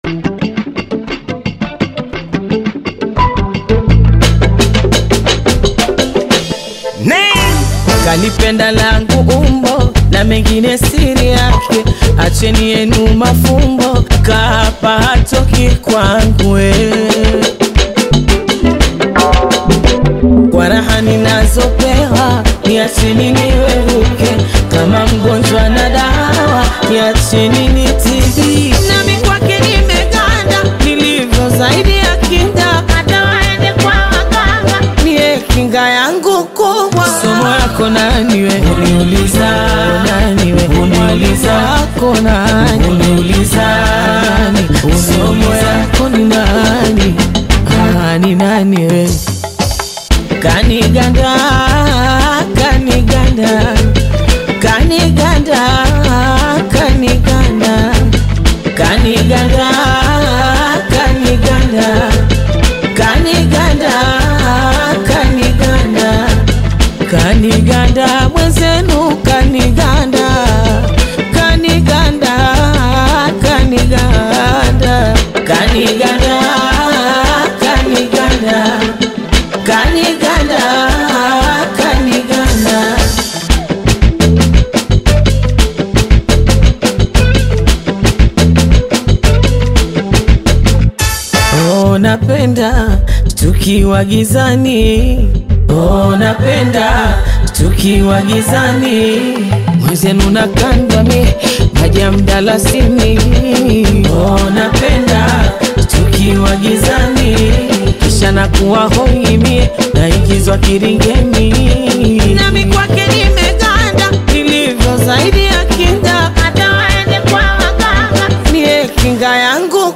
Taarabu